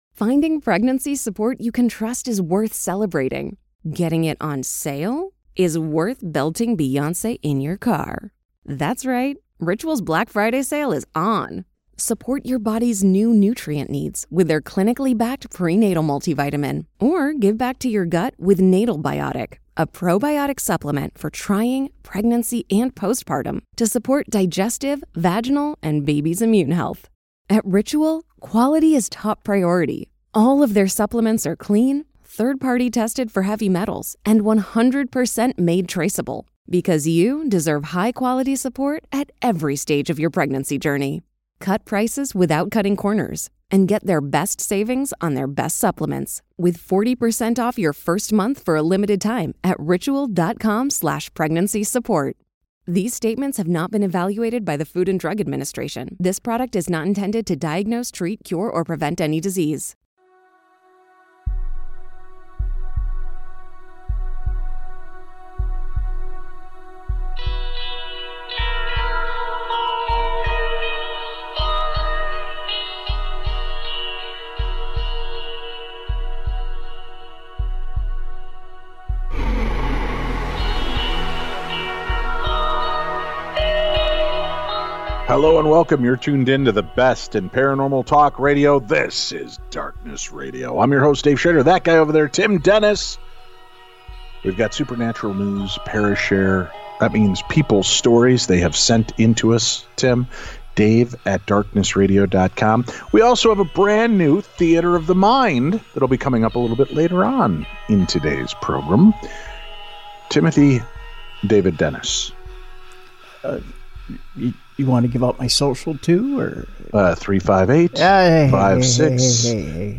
Plus a brand new Theater of the Mind and the boys read your emailed stories and questions.